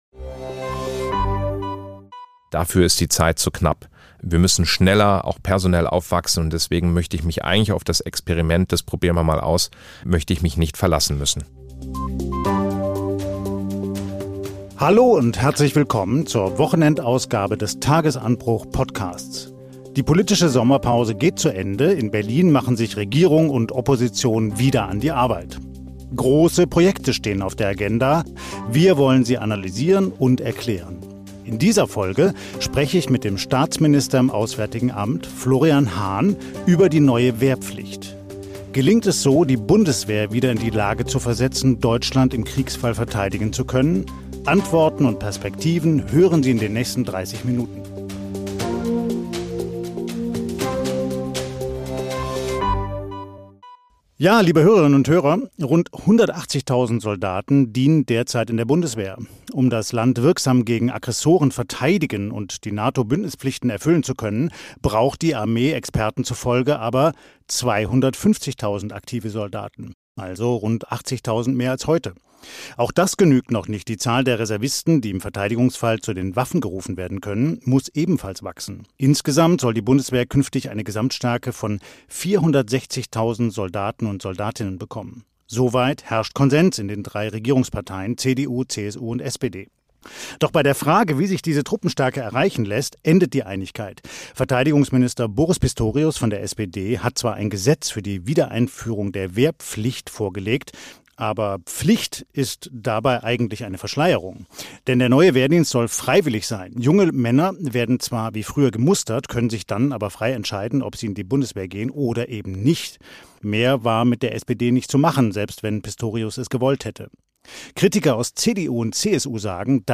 Wie kann Deutschland Putin abwehren? Der Staatsminister antwortet